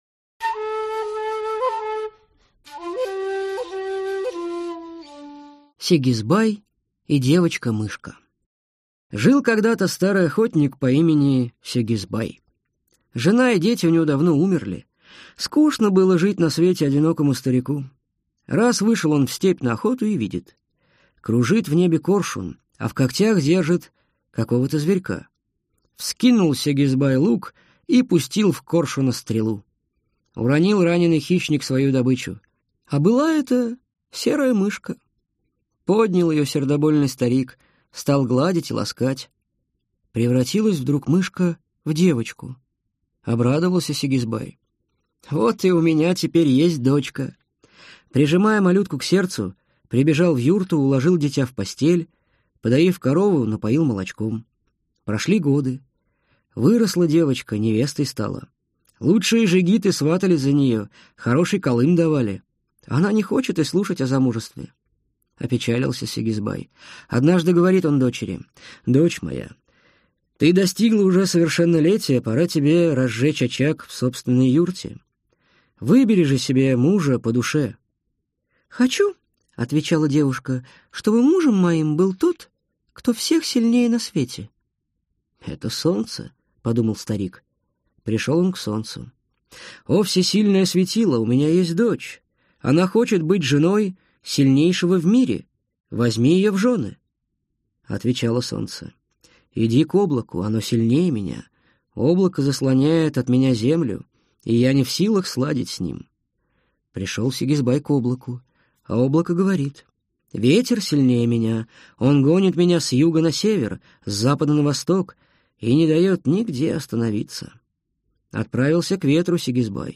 Аудиокнига Казахские народные сказки | Библиотека аудиокниг